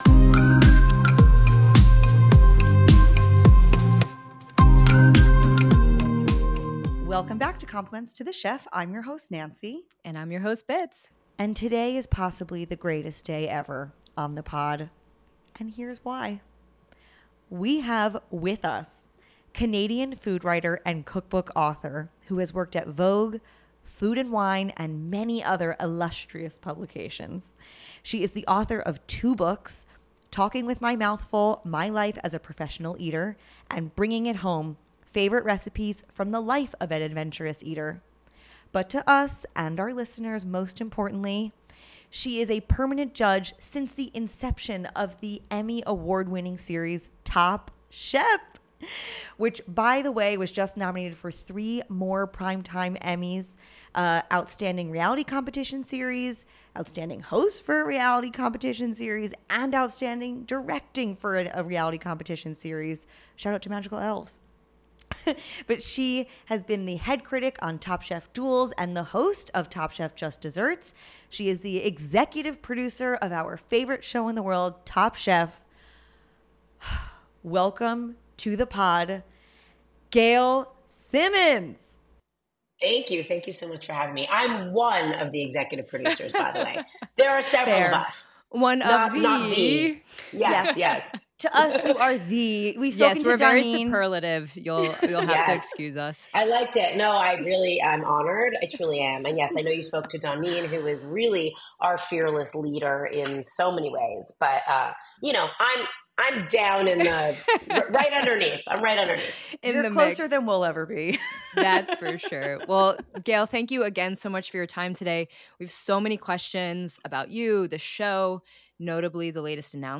Compliments to the Chef is honored to present our interview with the beating heart of Top Chef, the one, the only, the lovely Gail Simmons.